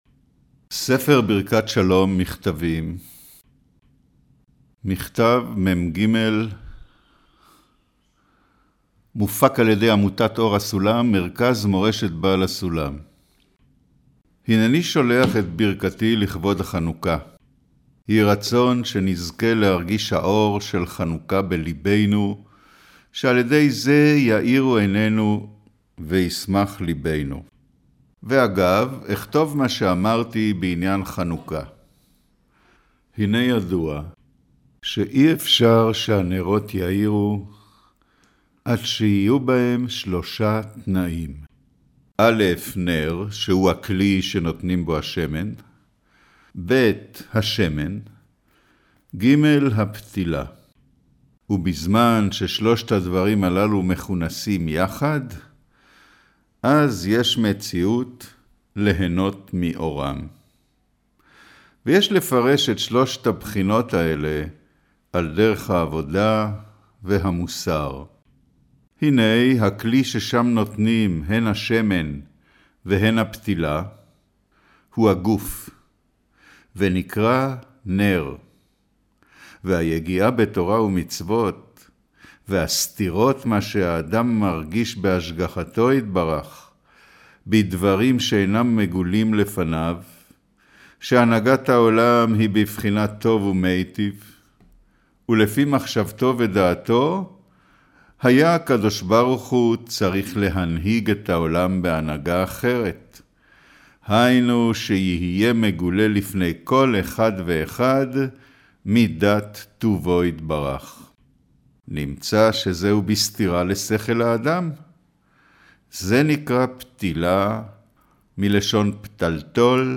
אודיו - קריינות אגרת מג'